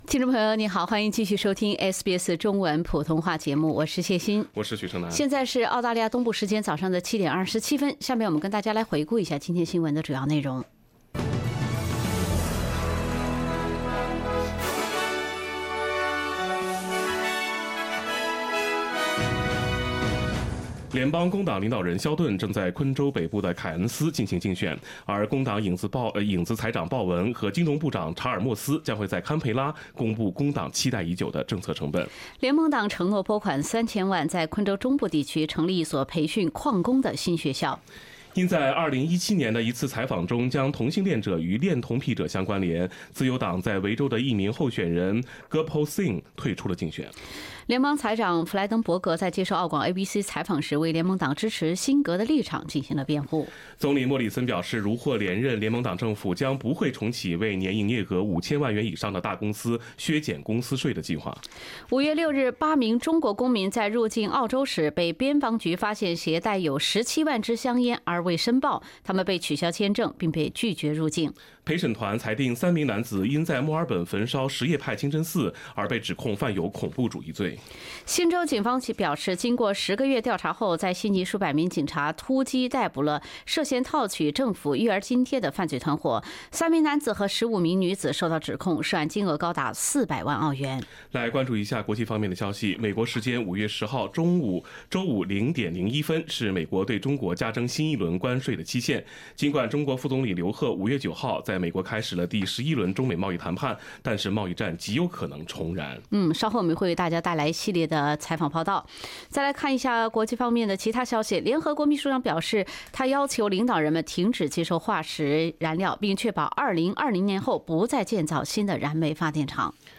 SBS 早新聞 （5月10日）